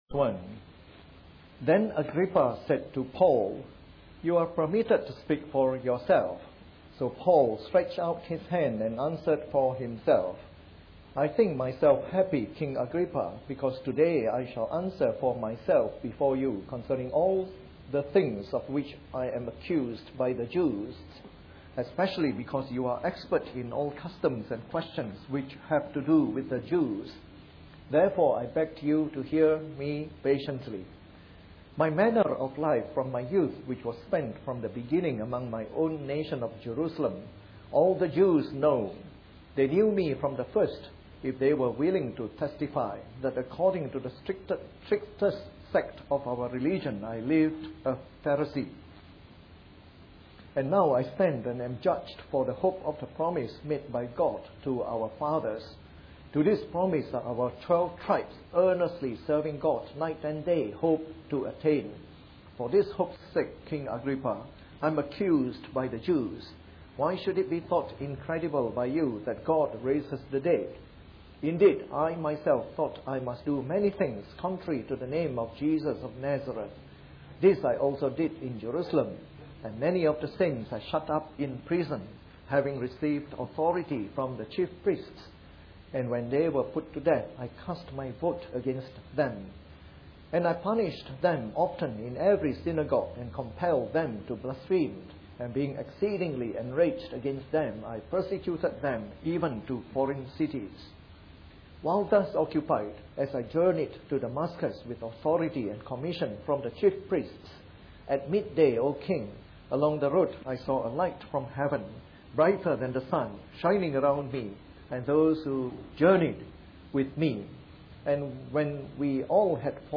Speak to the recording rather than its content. Part of our series on “The Acts of the Apostles” delivered in the Evening Service.